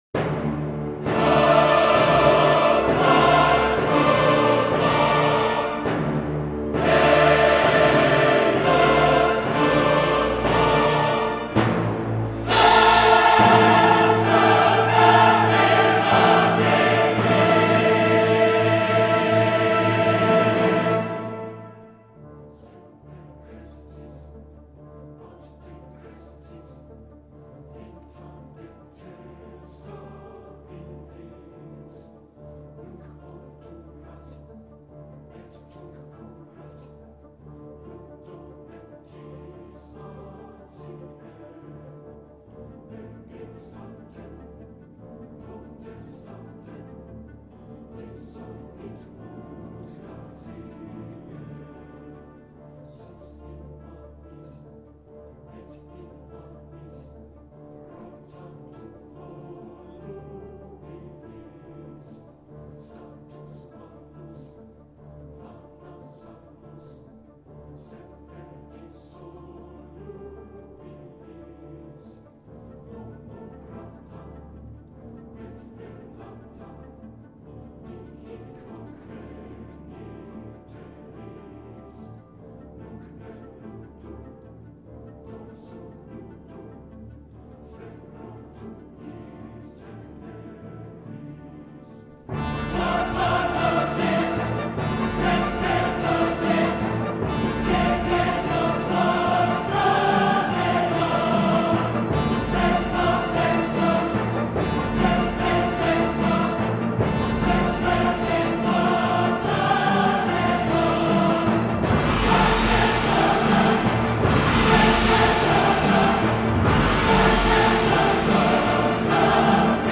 secular cantata